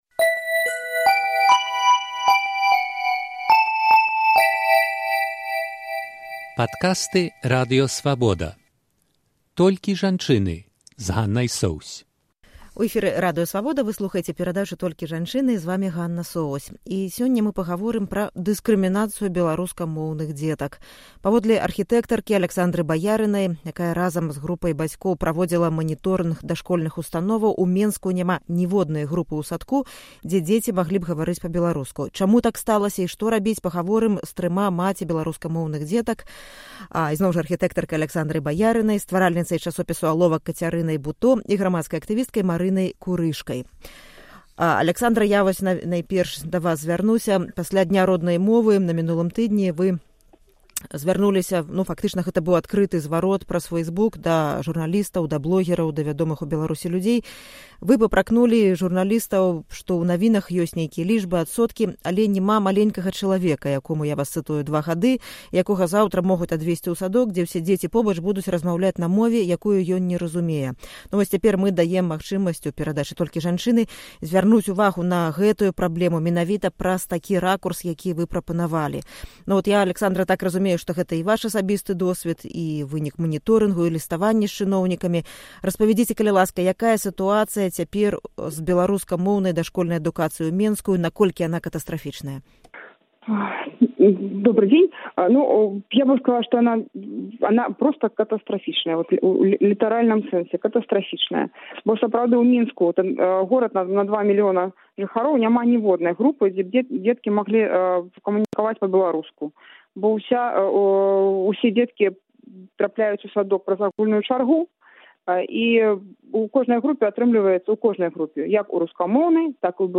Удзельніцы перадачы - тры маці беларускамоўных дзяцей